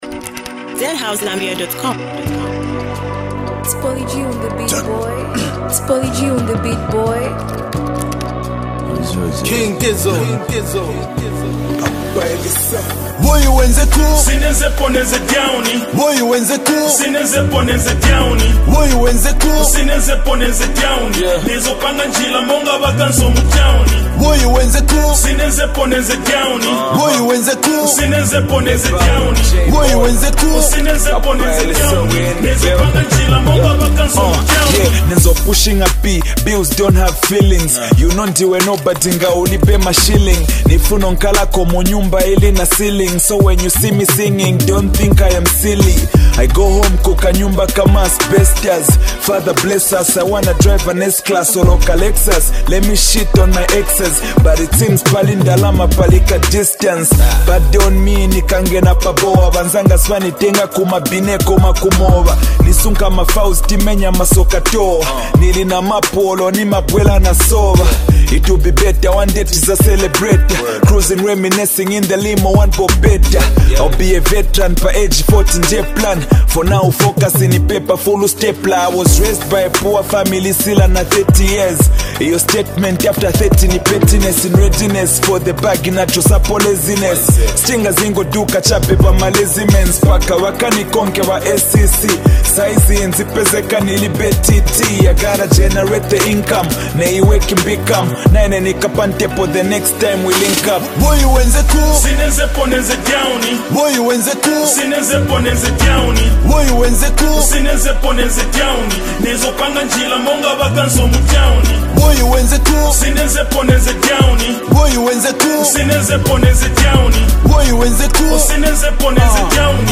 a banger